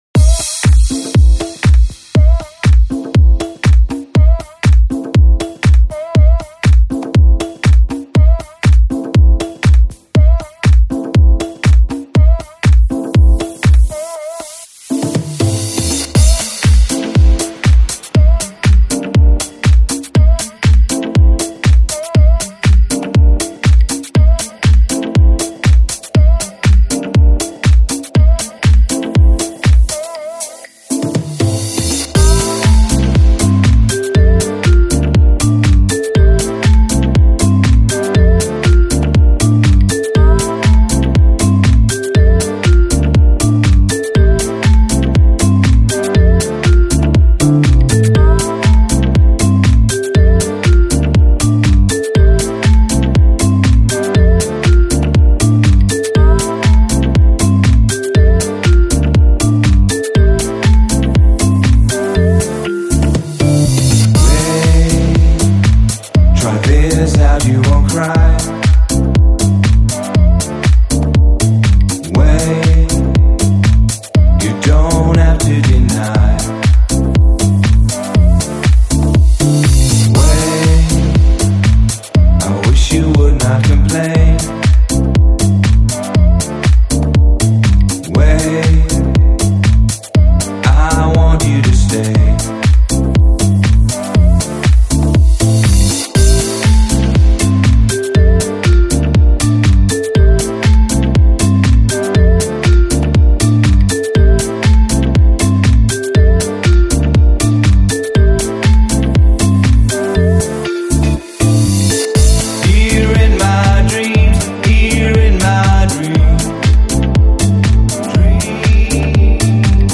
Жанр: Downtempo, Chill House